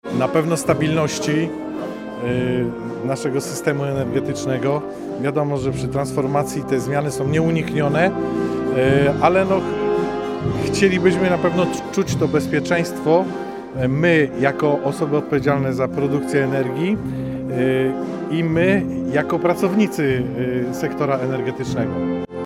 Po niej odbyła się uroczysta akademia.